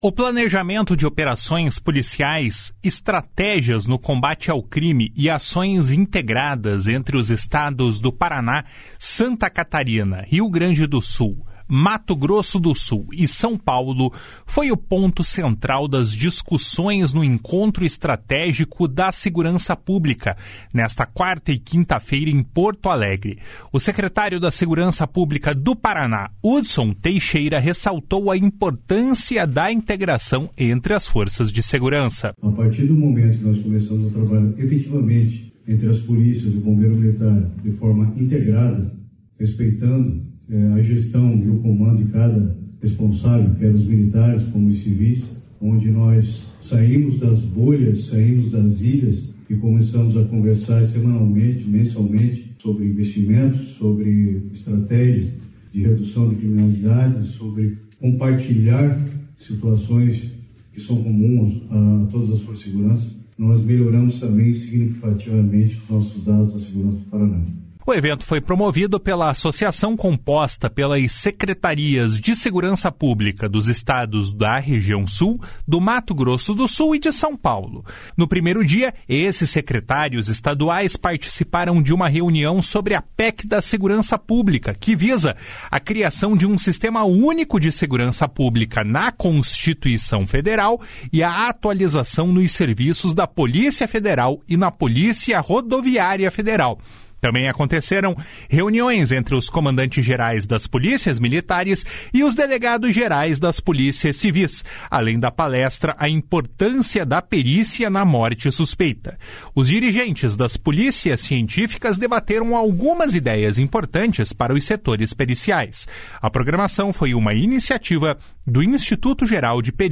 O planejamento de operações policiais, estratégias no combate ao crime e ações integradas entre os estados do Paraná, Santa Catarina, Rio Grande do Sul, Mato Grosso do Sul e São Paulo foi o ponto central das discussões no Encontro Estratégico da Segurança Pública, nesta quarta e quinta-feira, em Porto Alegre. O secretário de Segurança Pública do Paraná, Hudson Leôncio Teixeira, ressaltou a importância da integração entre as forças de segurança. // SONORA HUDSON LEÔNCIO TEIXEIRA //